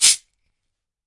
打击乐 " CABASA
描述：铁沙铃
Tag: 节奏 铁沙铃 打击乐器